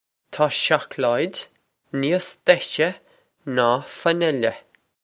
Taw shock-lloyd nee-os deh-sheh naw fan-ill-eh.
This is an approximate phonetic pronunciation of the phrase.